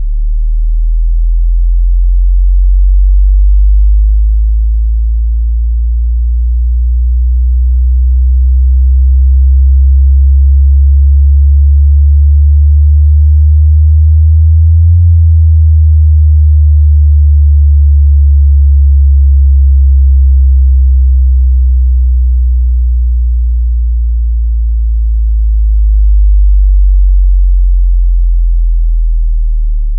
This is an experimental "prescription FM" signal used during LOAPEX 04 . It sweeps from 32 to 92 Hz.